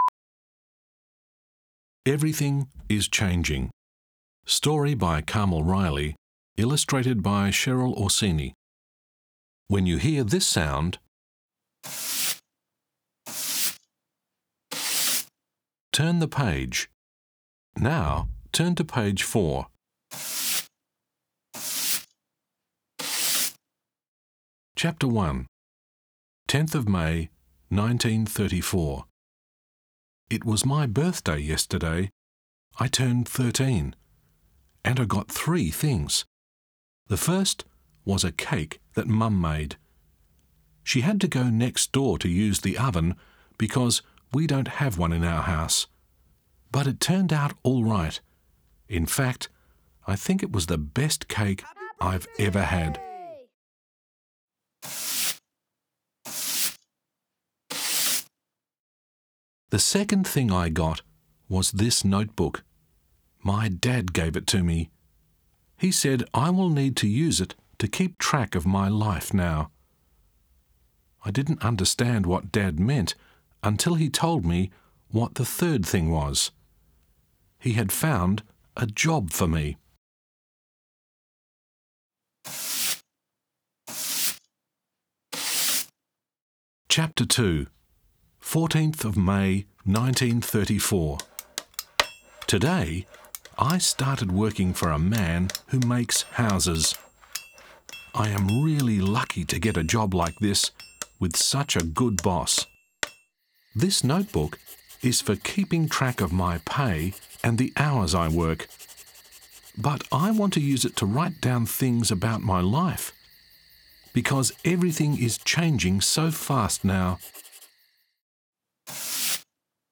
Type : Short Story